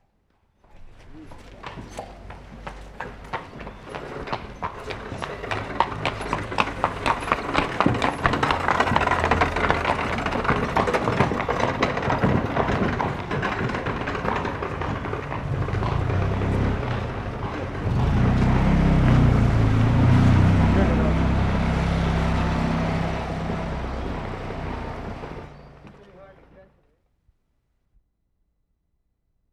WORLD SOUNDSCAPE PROJECT TAPE LIBRARY
Cembra, Italy April 1/75
CART PULLING UP TO HOUSE, followed by ambience at house.